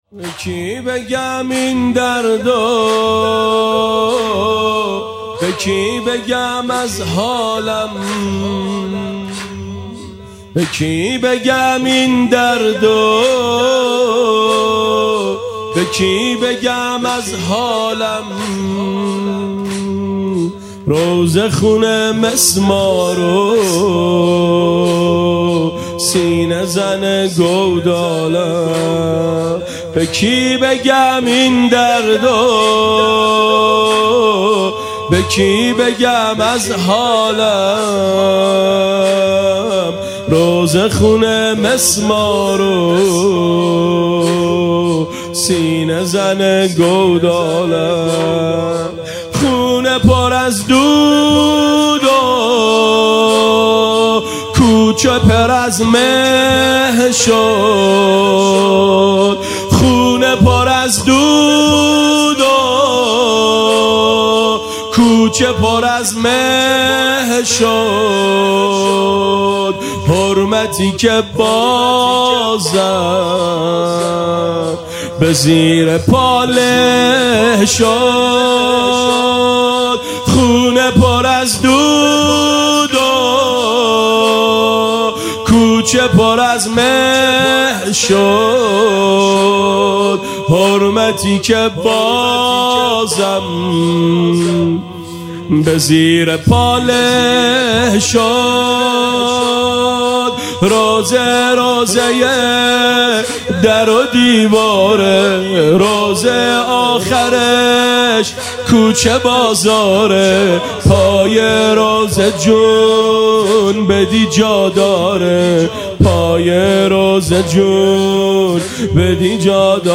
زمینه به کی بگم این درد؟
شهادت امام جعفر صادق علیه السلام، 5 خرداد ۴۰۱